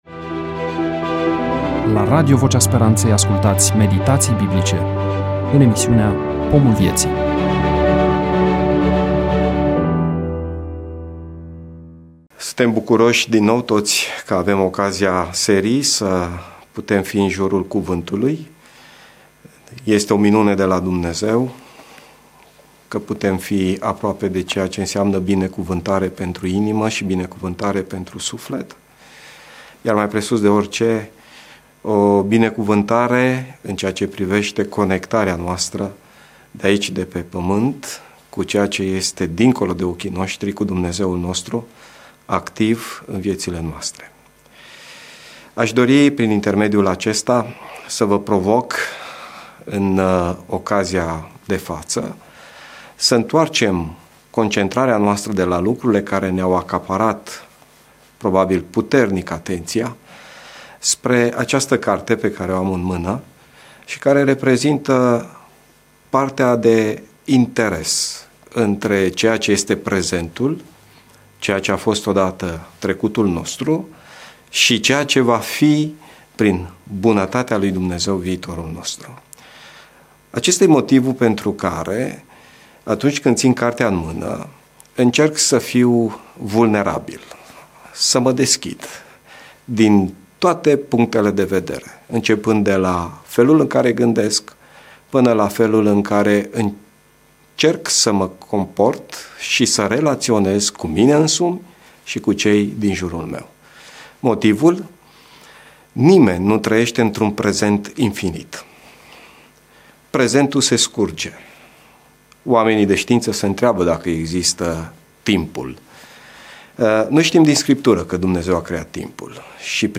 EMISIUNEA: Predică DATA INREGISTRARII: 28.11.2025 VIZUALIZARI: 14